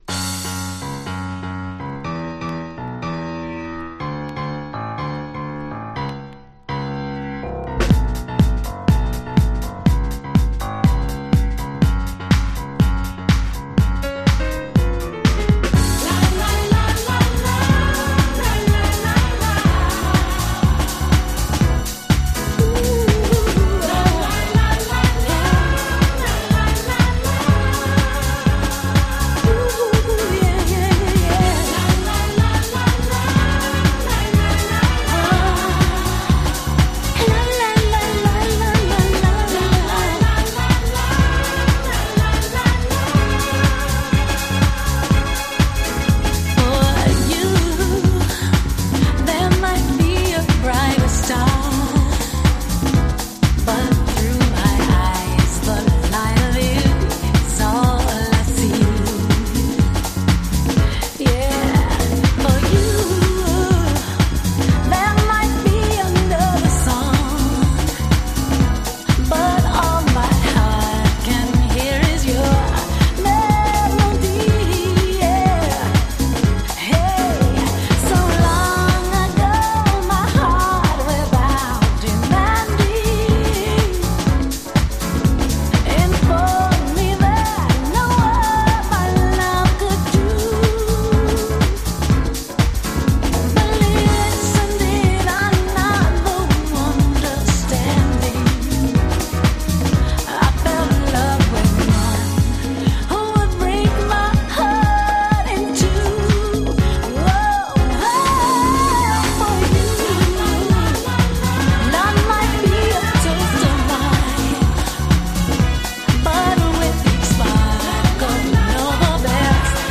大ヒットHouse !!
本当に格好良いVocal Houseです！！